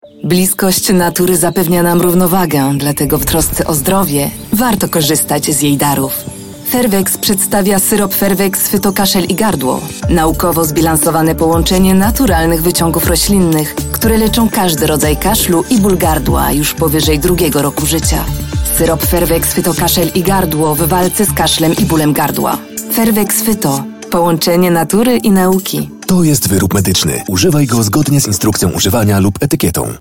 Stem
Commercieel, Vertrouwd, Zacht
Commercieel